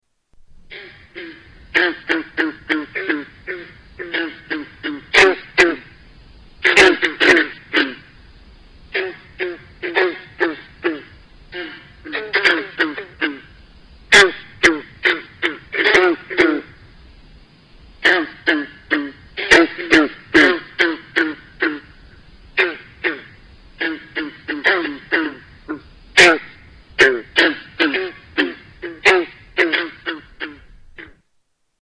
Green Frog